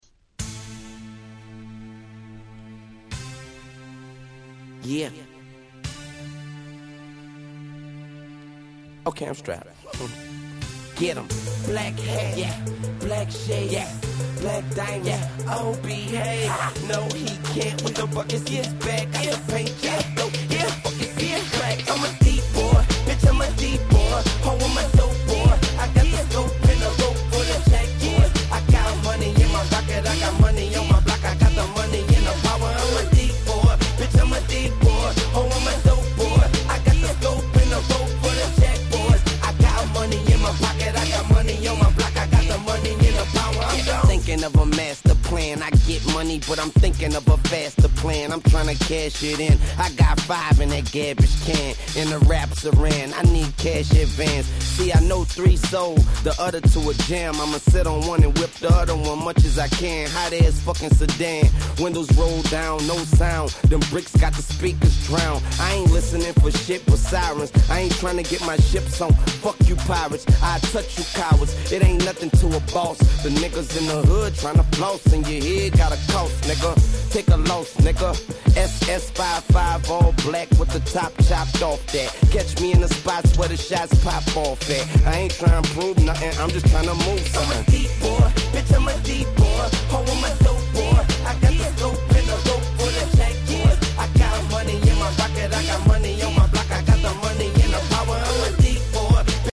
05' Super Hit Hip Hop LP !!
Dirty South寄りの曲が目立った前作から打って変わって本作はSoulfulでMellowな格好良い曲が満載。